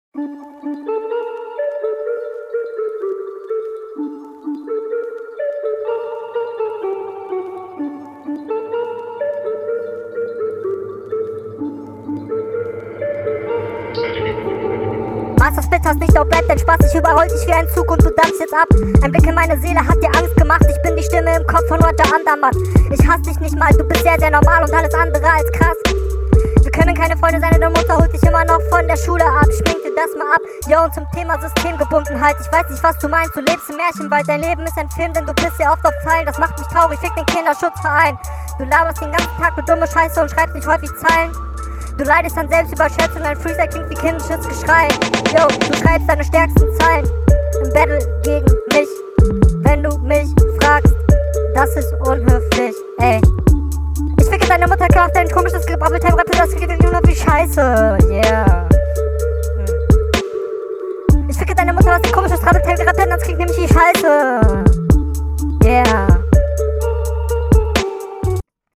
Flowlich sehe ich dich hier auf einen ähnlichen Level.